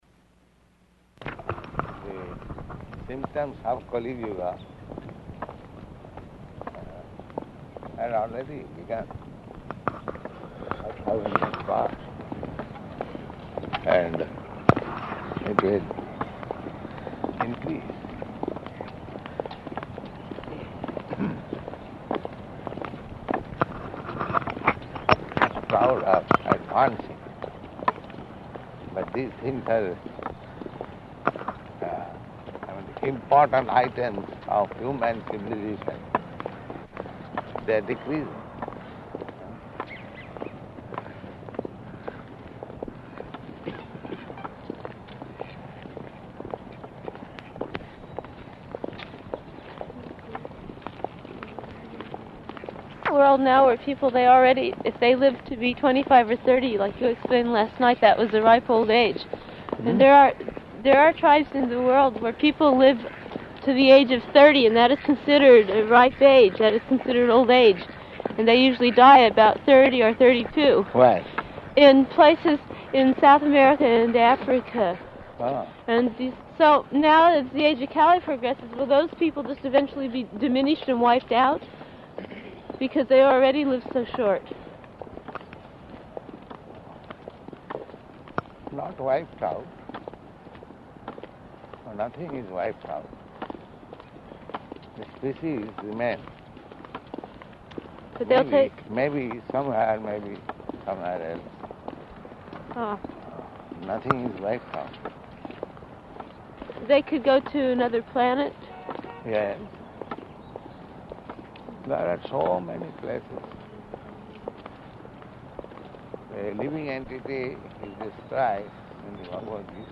Morning Walk [partially recorded]
Type: Walk
Location: San Francisco